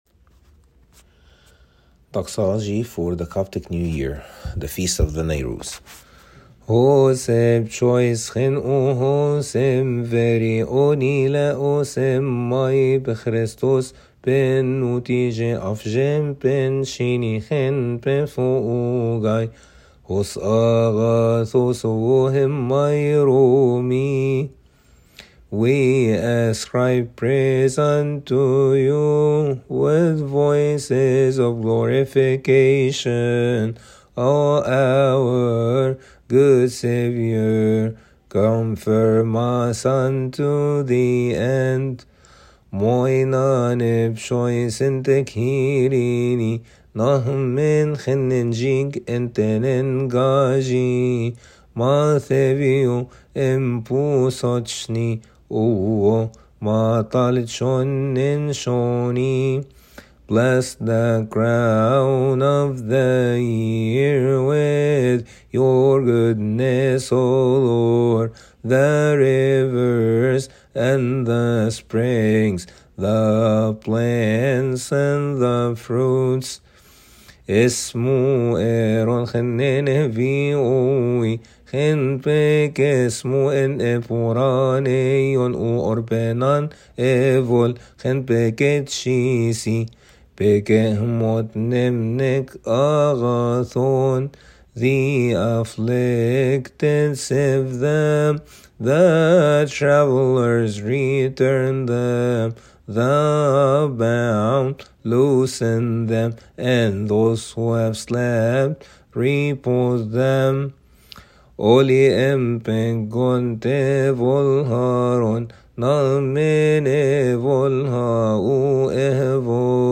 For the recording, please have the hymn first start in Coptic.